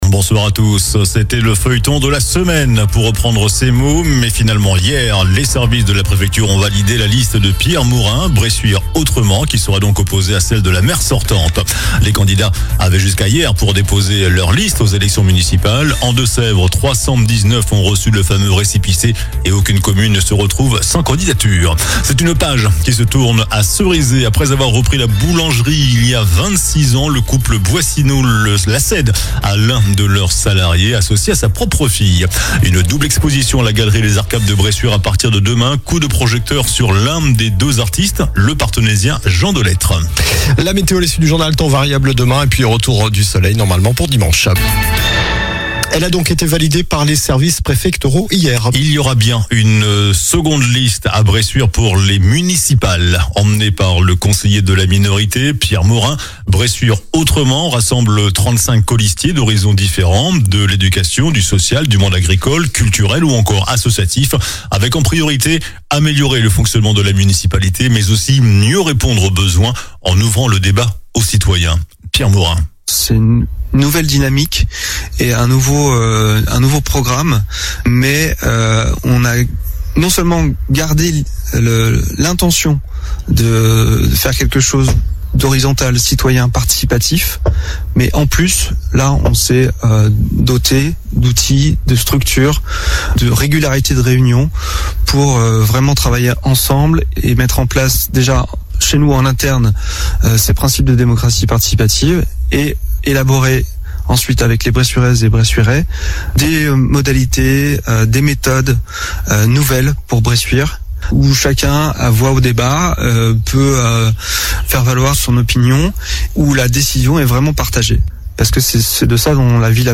JOURNAL DU VENDREDI 27 FEVRIER ( SOIR )